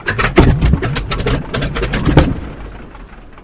На этой странице собраны звуки авиакатастрофы — от гула падающего самолета до тревожных сигналов и хаотичных шумов.
Внезапно у кукурузника заглох двигатель